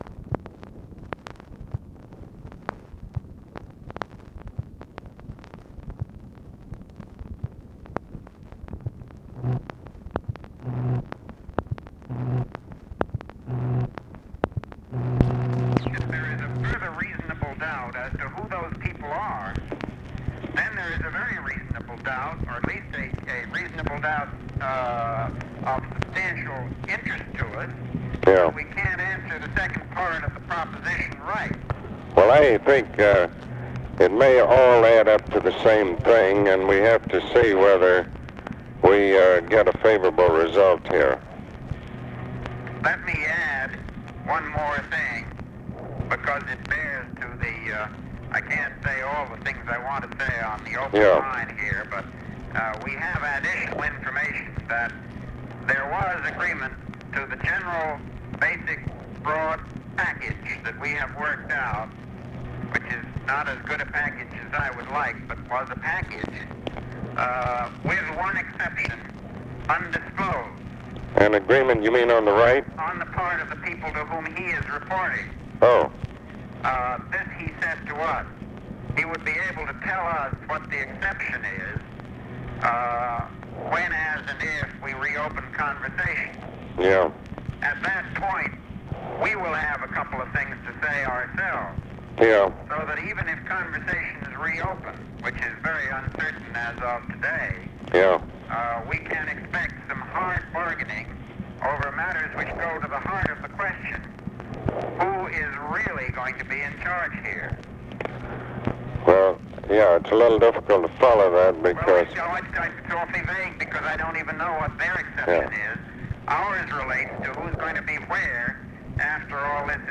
Conversation with MCGEORGE BUNDY and ABE FORTAS, May 18, 1965
Secret White House Tapes